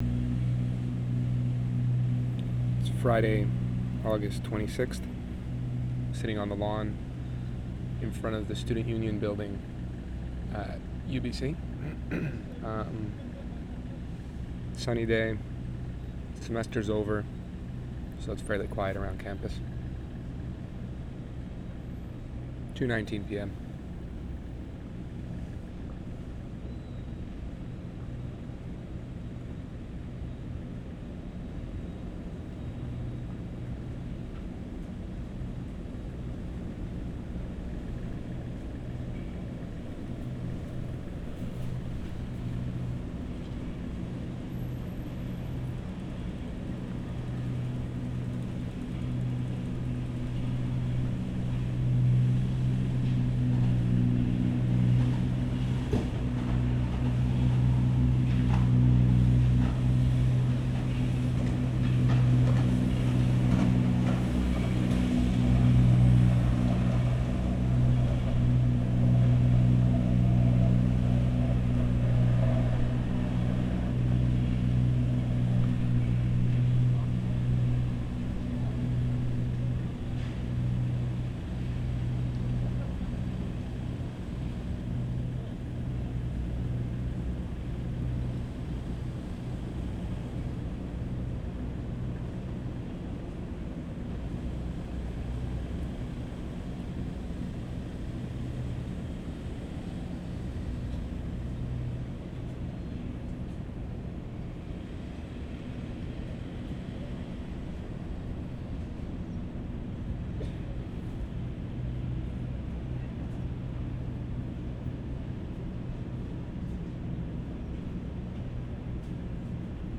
Student Union Building I, 5:30
6. Track ID @ intro, truck (or sidewalk cleaner) passes at 0:50, ambient voices of people relaxing on the lawn (I'm in front of the SUB at a distance of about 100 ft), bird chirping at 2:10, truck in reverse from a distance at 2:55 (construction ambience), truck in reverse at 3:35, loud crash at 4:23 - construction materials, truck passes at 5:17.